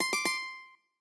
lute_c1d1c1.ogg